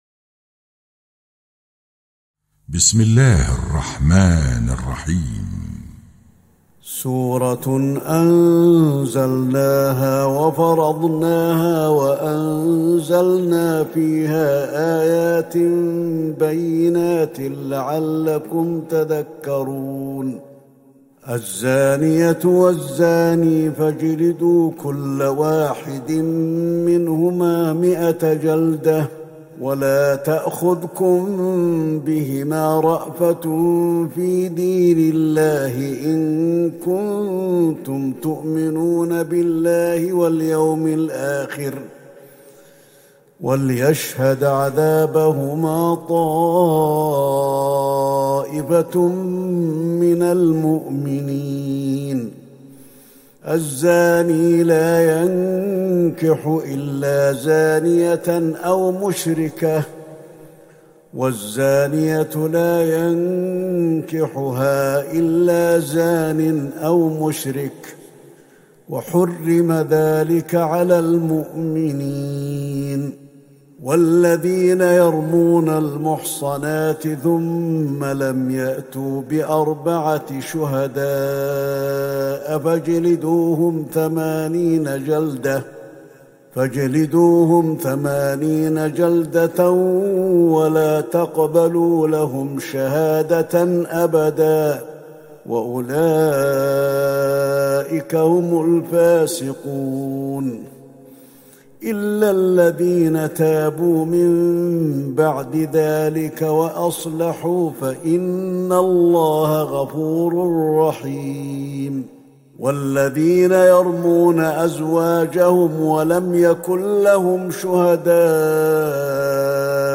تهجد ٢٢ رمضان ١٤٤١هـ من سورة النور { ١-٢٦ } > تراويح الحرم النبوي عام 1441 🕌 > التراويح - تلاوات الحرمين